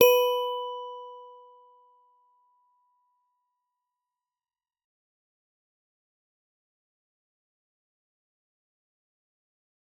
G_Musicbox-B4-f.wav